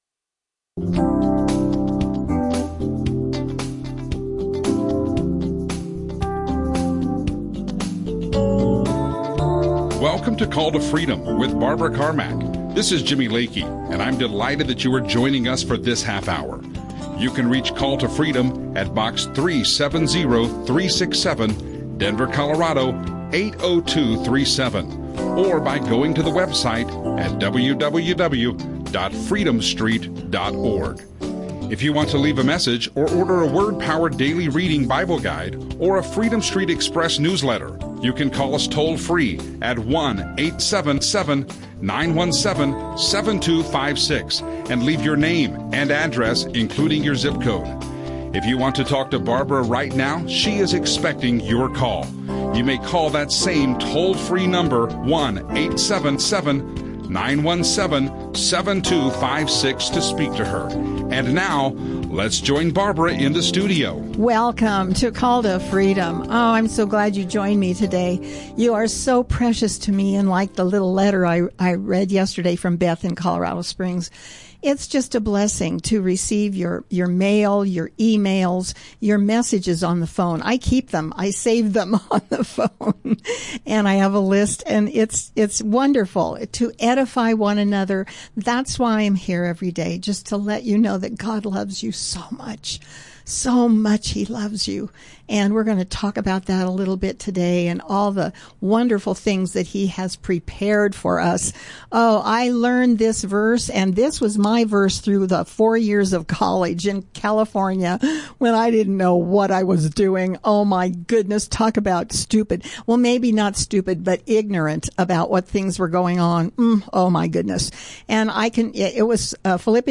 Christian radio show